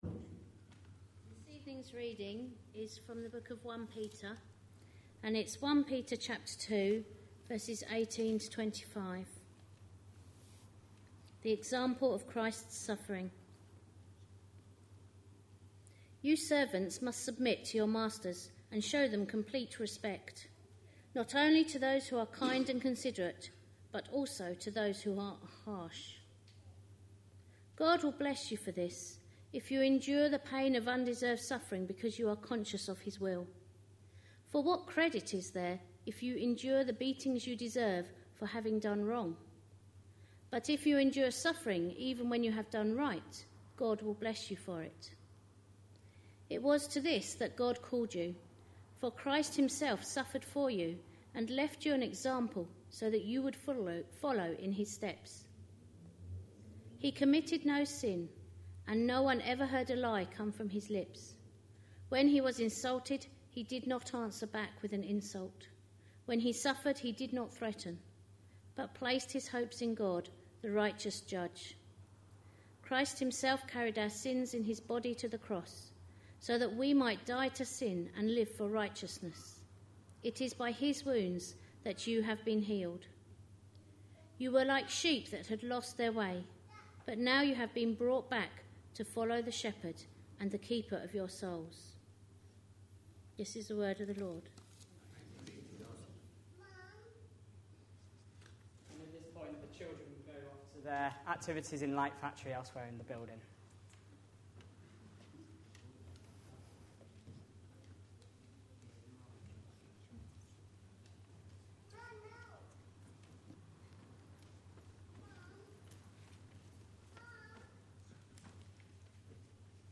A sermon preached on 21st November, 2010, as part of our A Letter to Young Christians series.